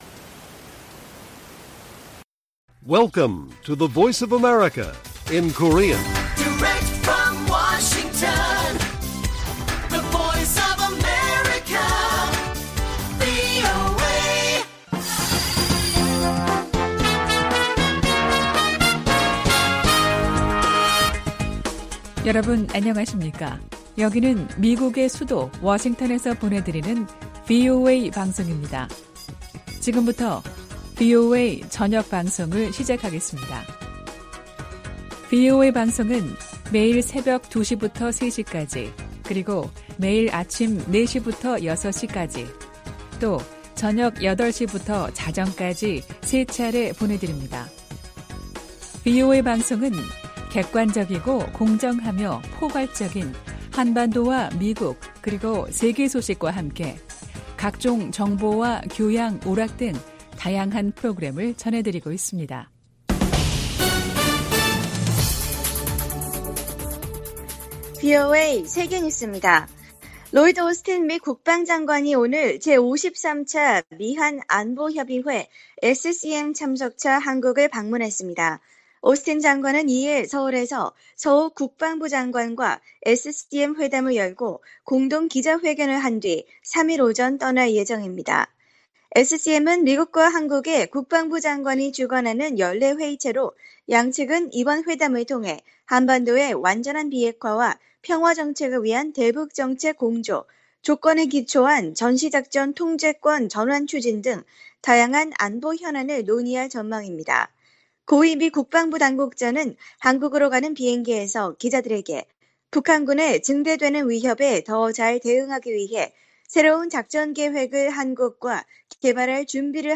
VOA 한국어 간판 뉴스 프로그램 '뉴스 투데이', 2021년 12월 1일 1부 방송입니다. 11월 중에 재개될 가능성이 거론됐던 북-중 국경 개방이 무산된 것으로 보입니다. 올해는 9년 만에 미국의 대북 독자 제재가 한 건도 나오지 않은 해가 될 가능성이 높아졌습니다. 한국전 실종 미군 가족들이 미국 정부에 유해 발굴 사업을 정치적 사안과 별개로 추진하라고 요구했습니다.